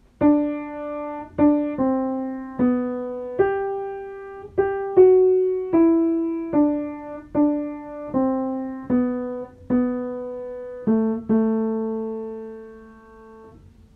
TENOR I: Hymn #220: Lord, I Would Follow Thee
Audition Key: G major* |
Starting Pitch: D | Sheet Music
tenor-1-hymn-220-g-major-m4a